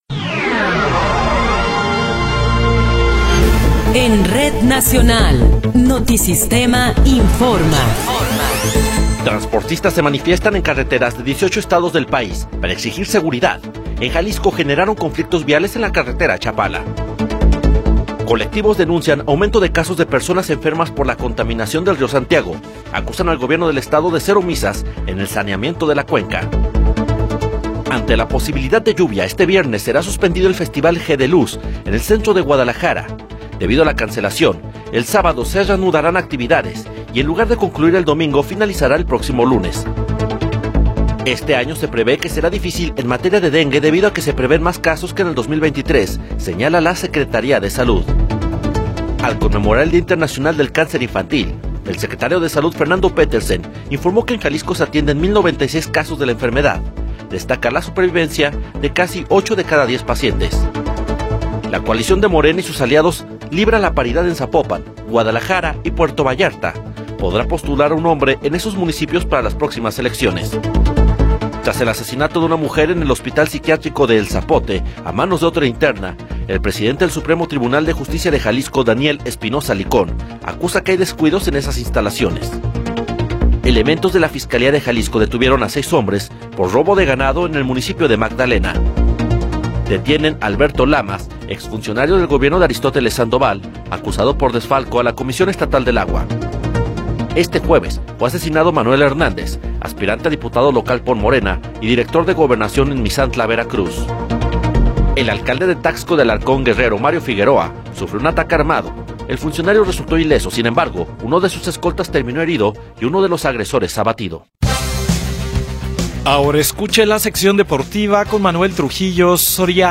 Noticiero 21 hrs. – 15 de Febrero de 2024
Resumen informativo Notisistema, la mejor y más completa información cada hora en la hora.